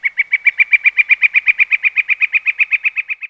Birdcall
Birdcall.wav